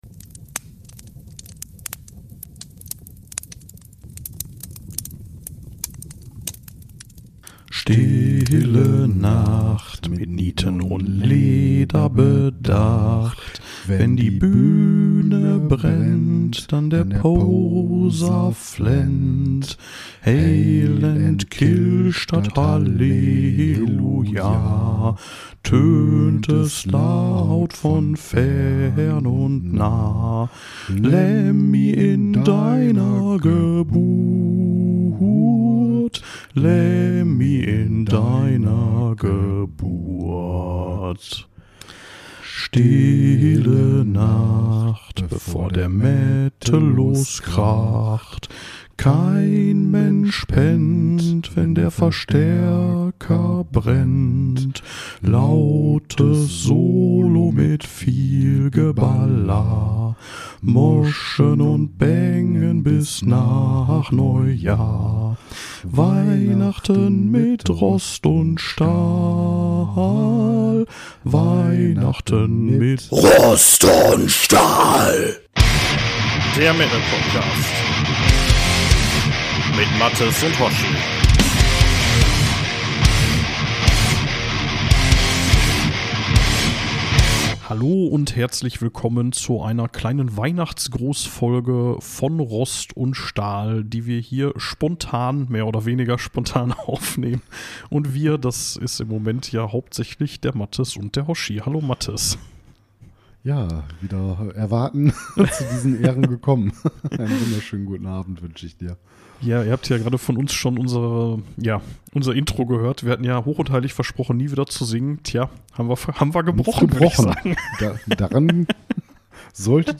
Wir haben -entgegen aller Beteuerungen- mal wieder gesungen und wir reden ein bisschen über euer Feedback. Eine Jahresabschlussfolge kommt noch, dennoch blicken wir auf ein nicht ganz so leichtes Jahr für Rost & Stahl zurück und freuen uns auf die Dinge, die da im nächsten Jahr kommen.